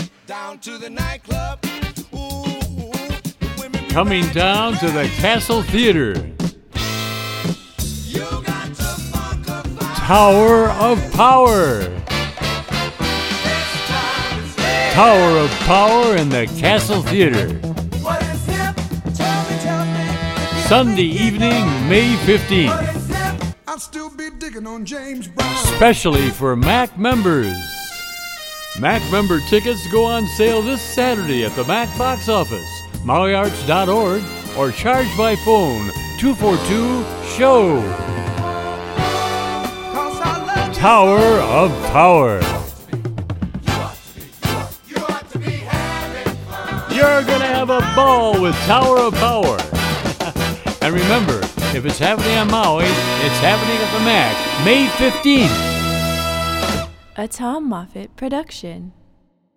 Long considered one of the greatest horn sections ever
has labeled it as “urban soul music.”